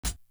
Grand Entry Hat.wav